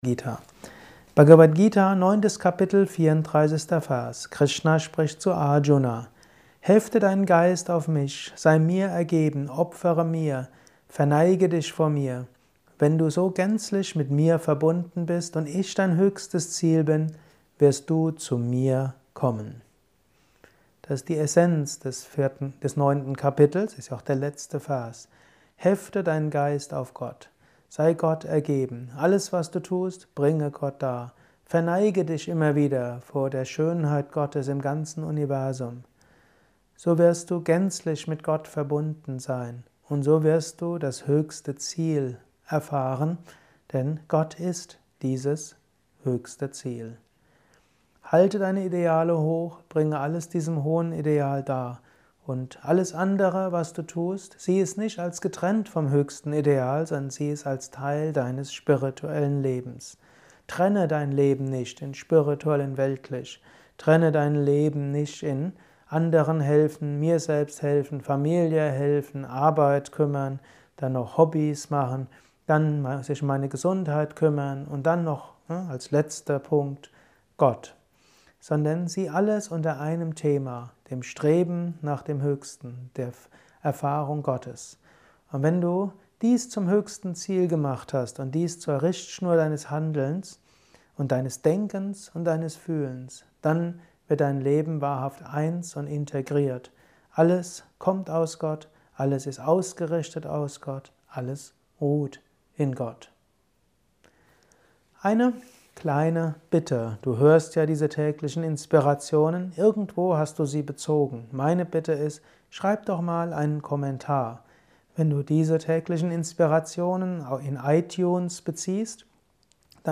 Dies ist ein kurzer Kommentar als